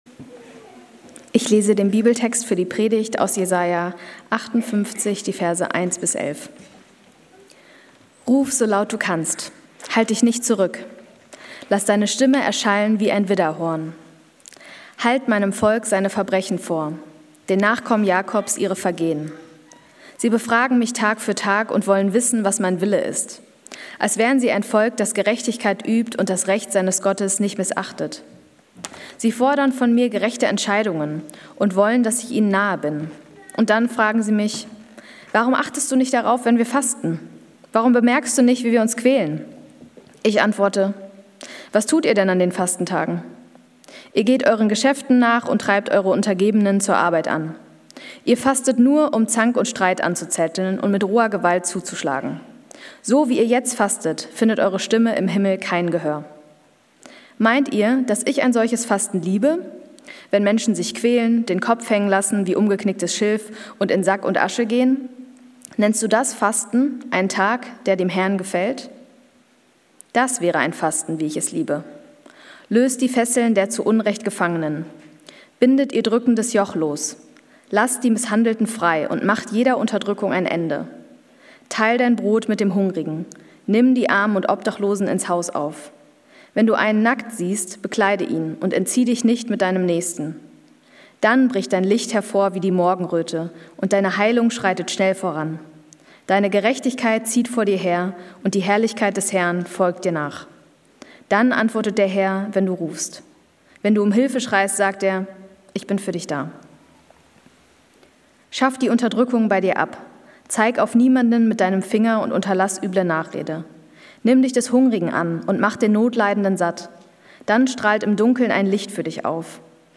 Diese Predigten wurden in den Gottesdiensten des Berlinprojekts gehalten und sind für den persönlichen Gebrauch bestimmt.